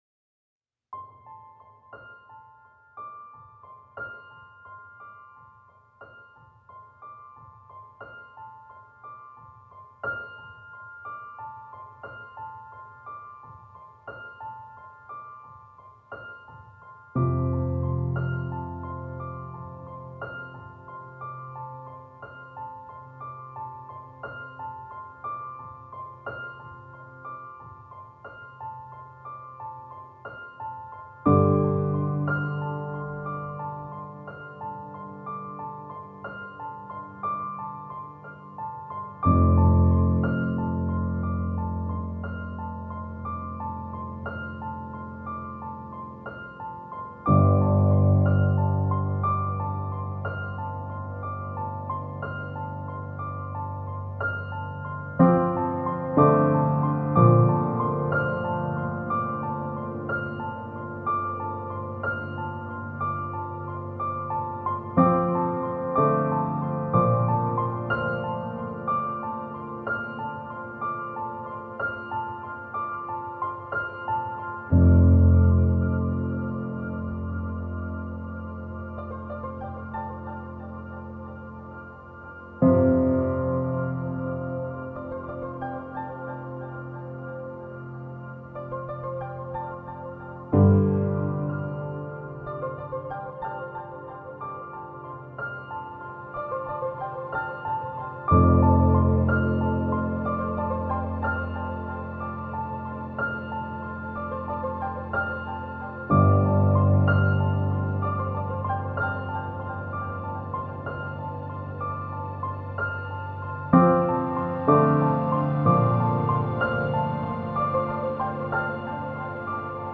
Delicate, innocent piano creating beauty through simplicity.